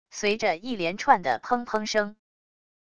随着一连串的砰砰声wav音频